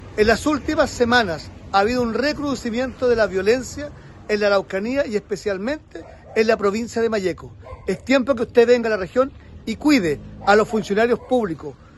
A esa solicitud se sumó el diputado de la UDI Henry Leal, quien afirmó que es necesario que la autoridad demuestre voluntad para proteger a los funcionarios públicos.